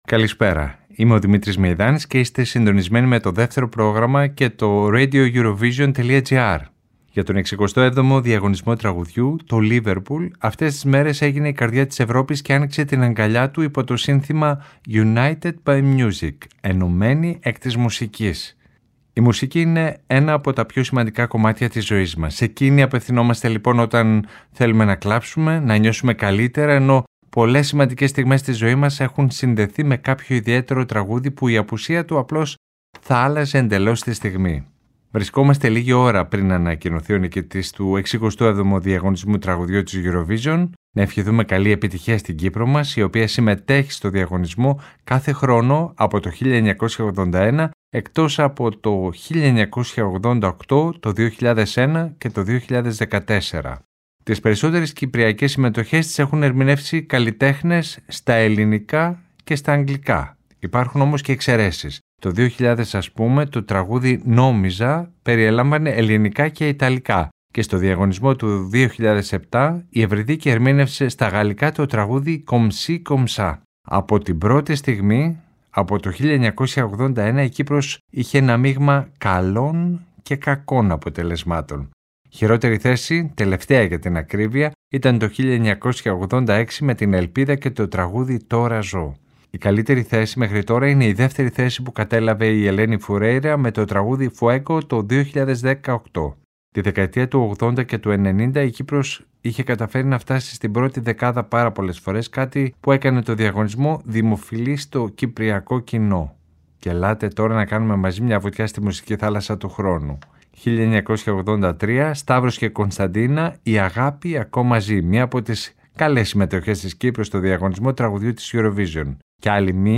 Η δημοφιλής ερμηνεύτρια Κωνσταντίνα είναι προσκεκλημένη